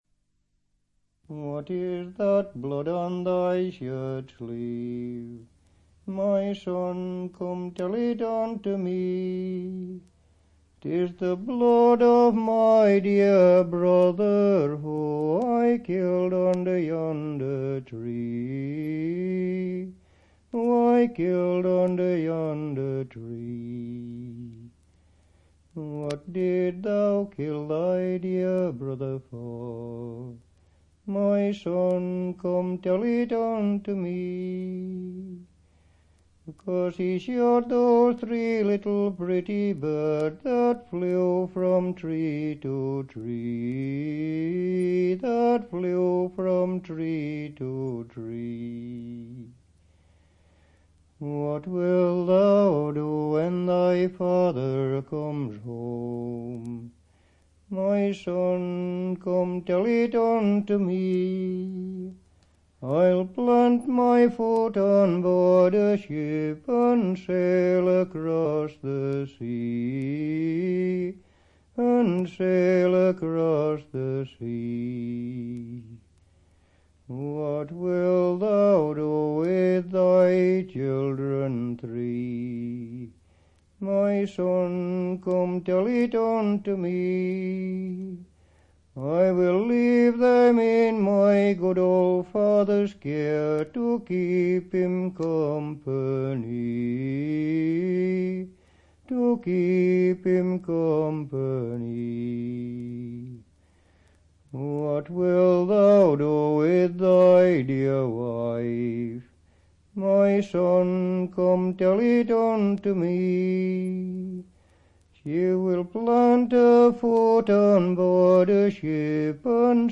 Crosspool, Sheffield
4/4 and various